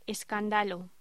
Locución: Escandallo